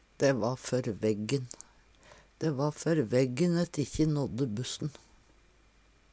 dæ æ før veggen - Numedalsmål (en-US)